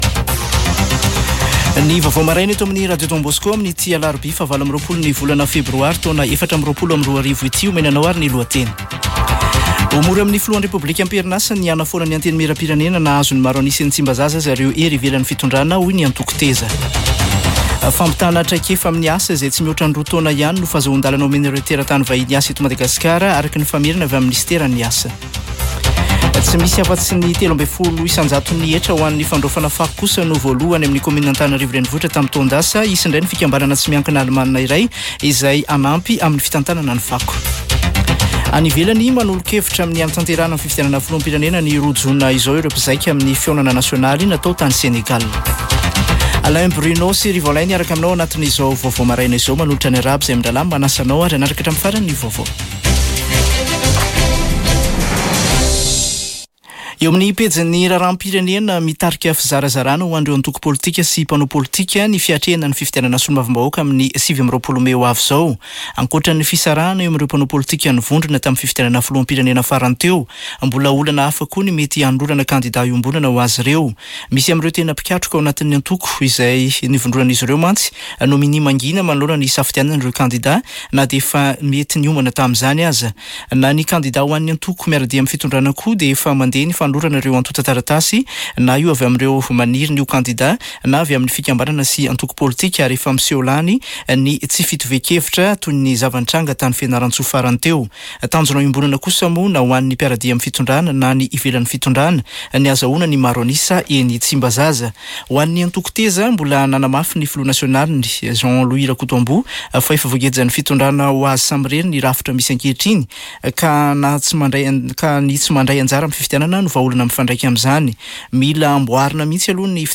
[Vaovao maraina] Alarobia 28 febroary 2024